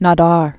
(nä-där) Originally Gaspard-Félix Tournachon. 1820-1910.